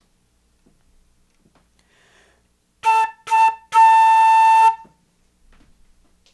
The following audio files illustrate the difference in sound between the instruments.
Armstrong 104 Flute
Click here to download a windows audio file of a upper-ranged tone being played on a modern flute.
armstrong_flute_high.wav